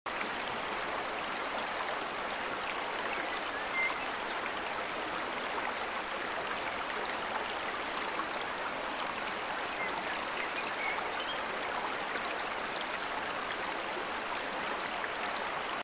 The sounds of nature while sound effects free download
The sounds of nature while meditating, the sound of flowing water 🌿 It's a green mountain.